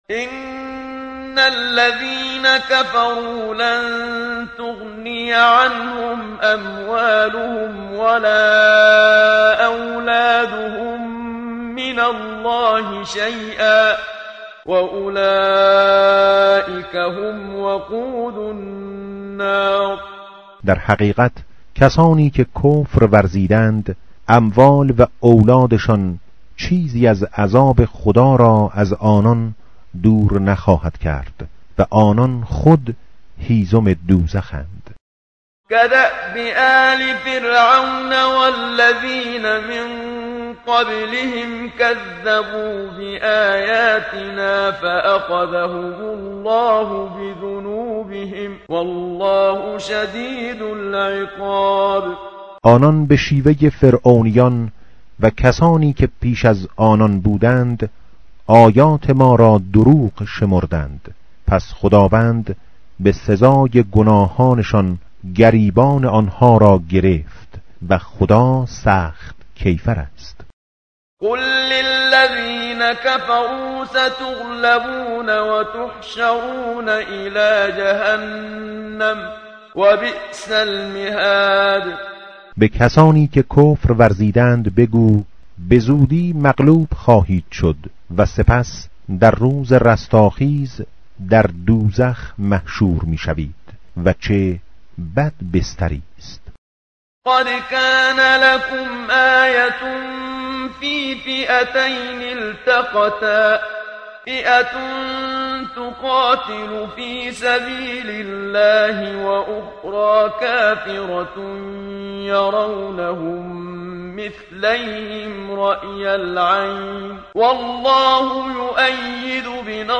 متن قرآن همراه باتلاوت قرآن و ترجمه
tartil_menshavi va tarjome_Page_051.mp3